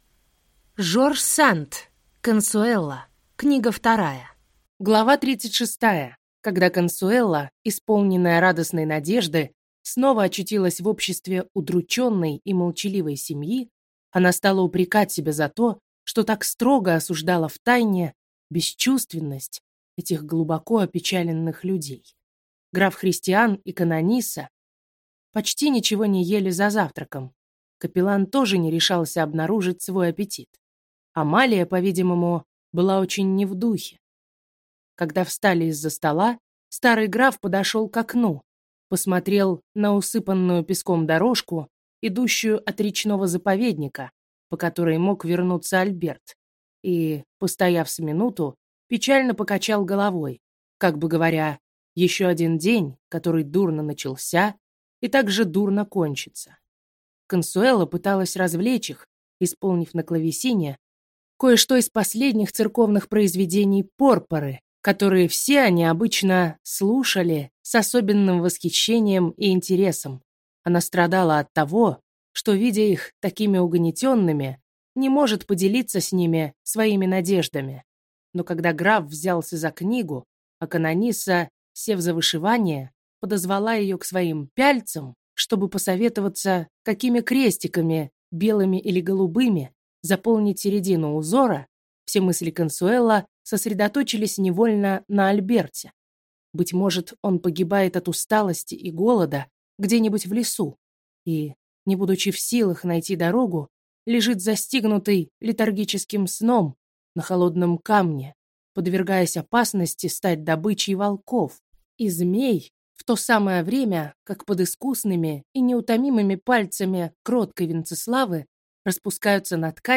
Аудиокнига Консуэло. Книга 2 | Библиотека аудиокниг